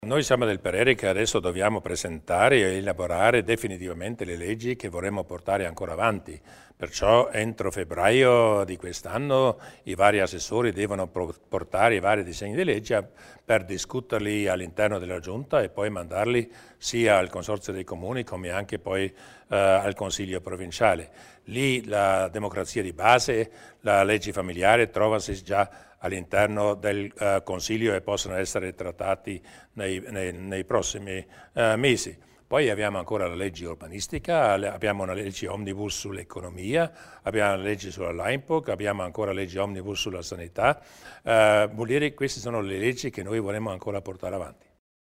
Il Presidente Durnwalder spiega le priorità legislative per i prossimi 6 mesi